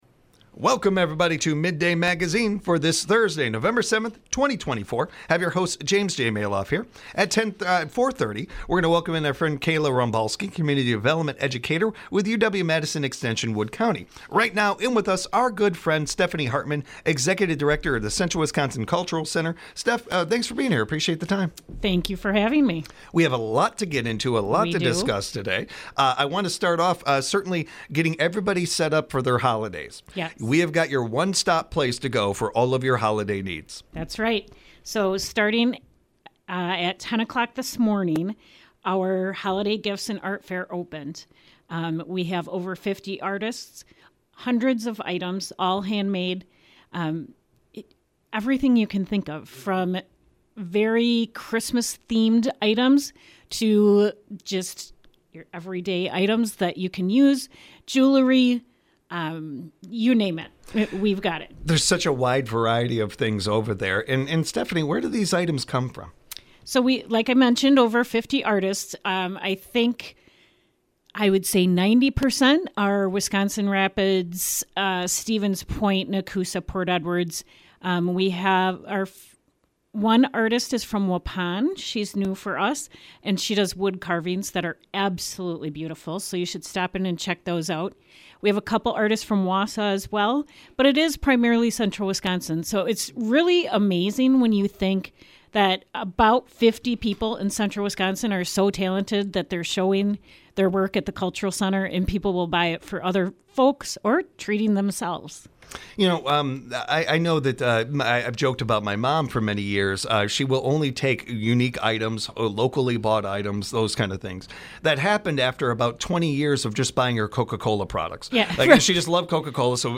Mid-day Magazine gives you a first look into what’s happening in the Central Wisconsin area. WFHR has a variety of guests such as non-profit organizations, local officials, state representatives, event coordinators, and entrepreneurs.